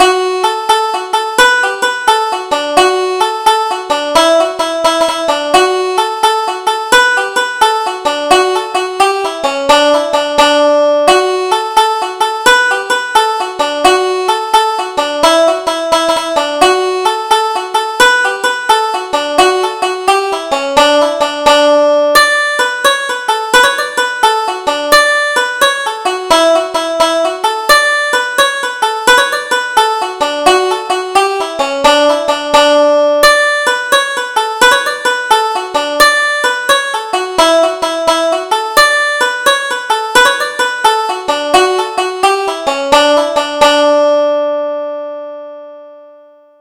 Double Jig: Martin's Onehorned Cow